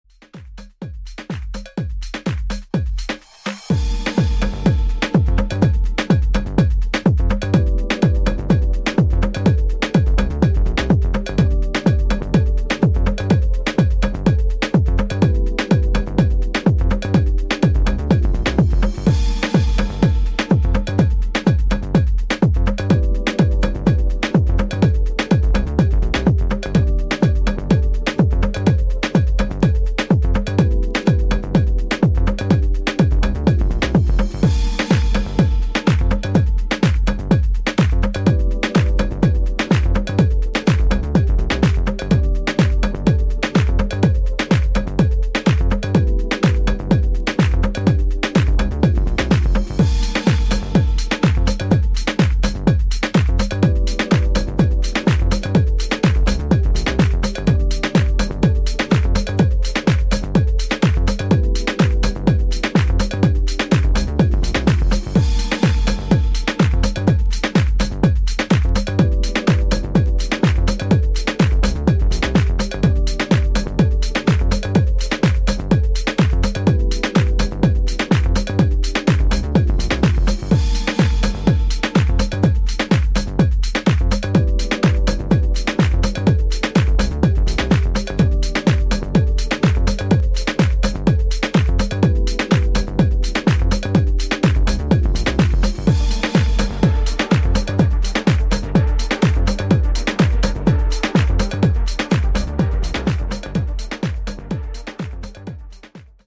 ジャンル(スタイル) DEEP HOUSE / DETROIT